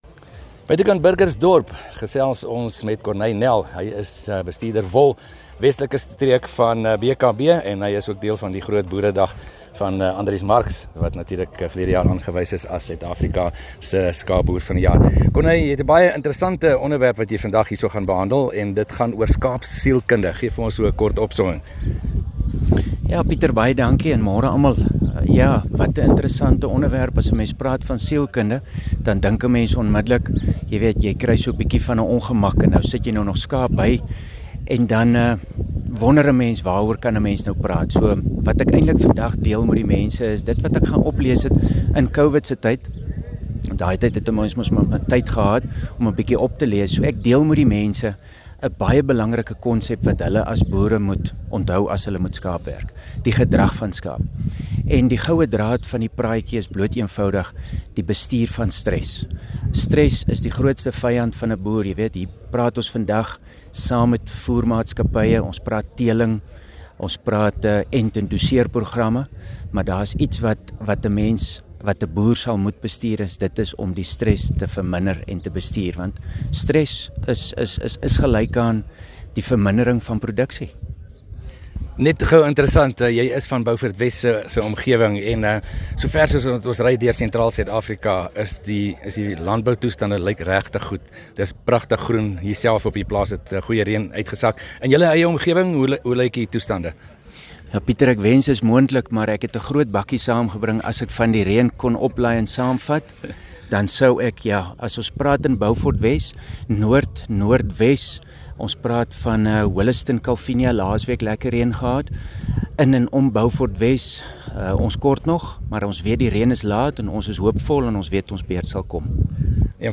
gesels met 'n bekende veekundige